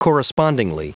Prononciation du mot correspondingly en anglais (fichier audio)